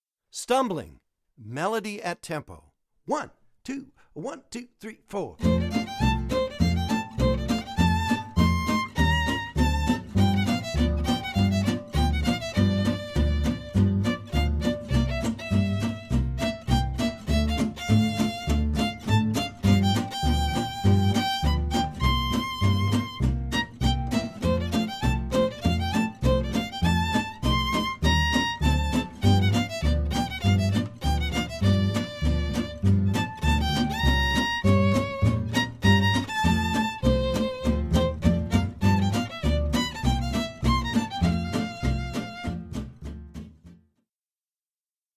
melody at tempo 1:33 10b.